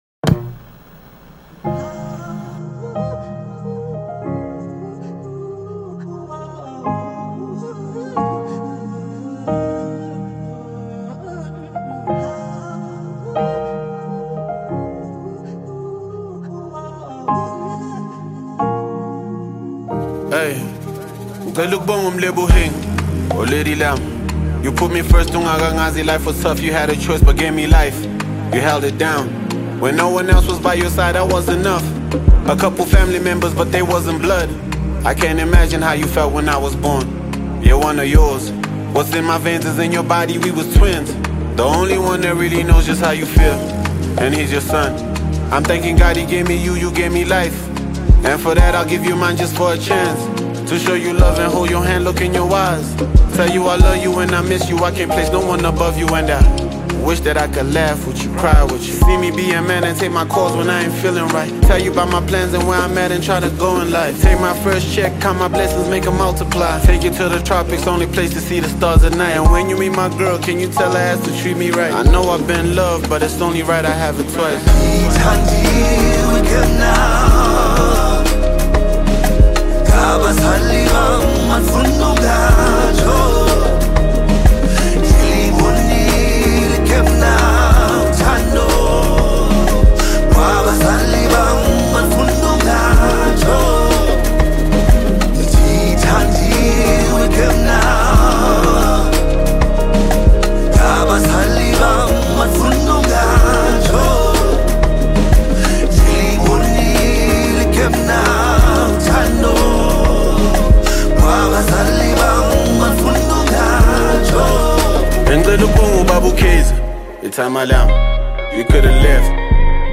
Award winning singer